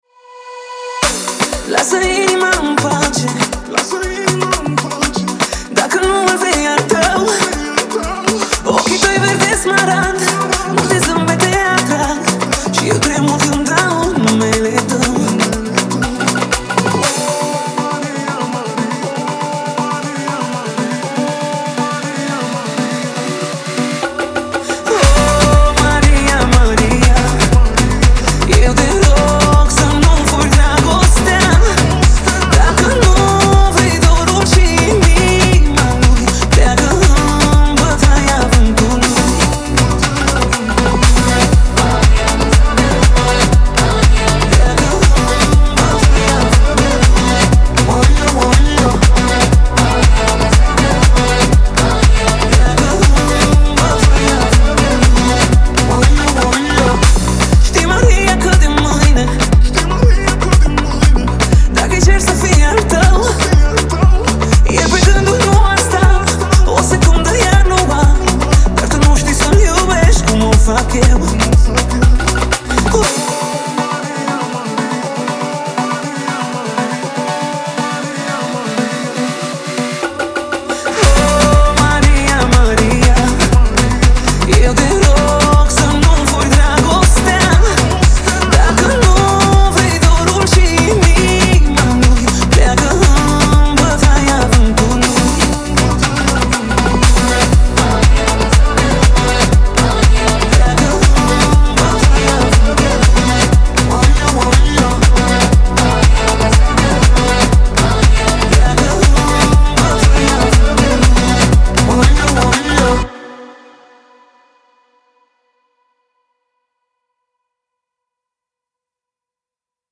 • Жанр: Pop, Dance